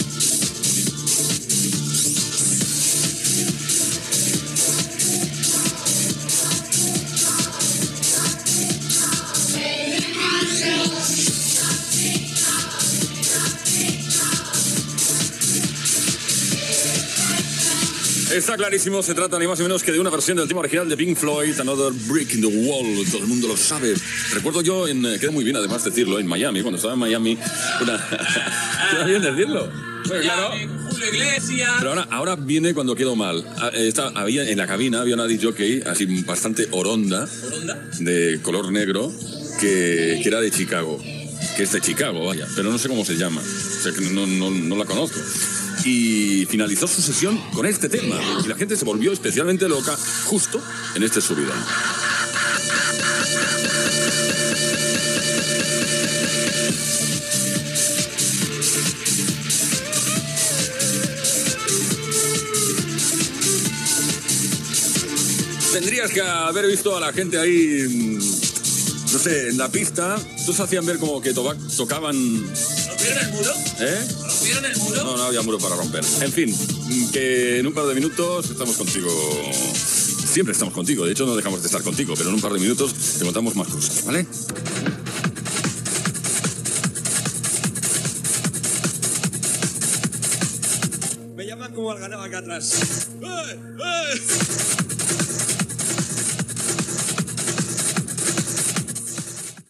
Tema musical, comentari sobre el tema que sonava i d'una DJ de Chicago que treballava en una discoteca de Miami, tema musical
Musical